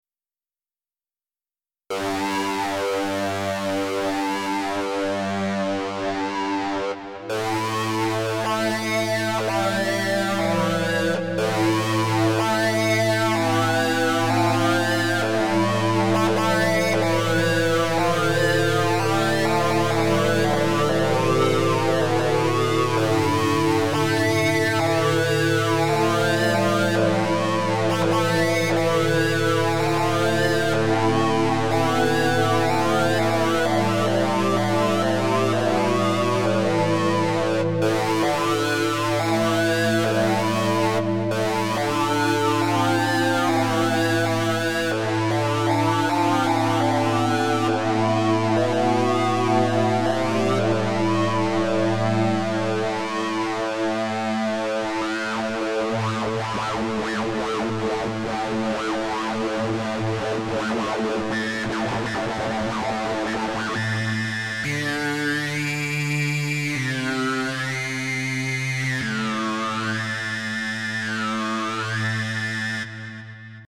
Ich hatte Gelegenheit den Quad Synth in Reason 8 zu testen. Der Quad ist 16 stimmig und bringt 2 Osciallatoren, 2 LFOs, 2 Envelopes und noch einiges mehr an den Start.